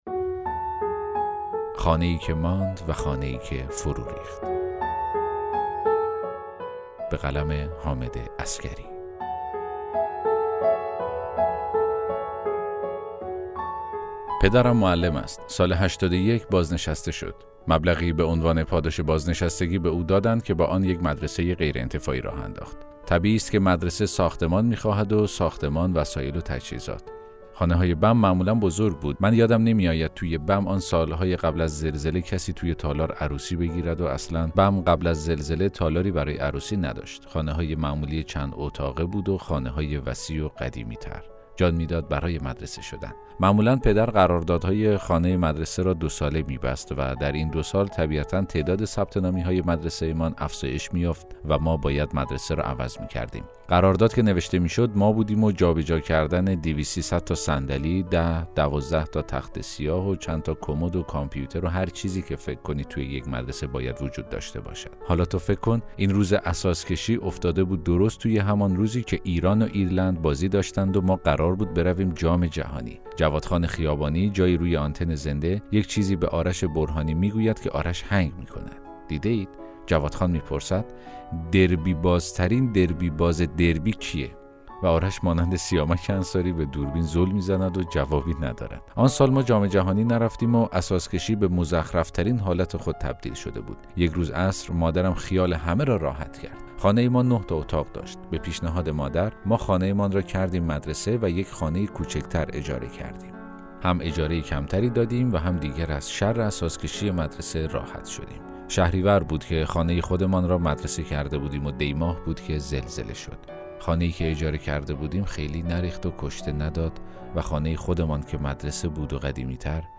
داستان صوتی: خانه‌ای که ماند و خانه‌ای که فروریخت